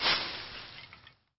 cutgrass.mp3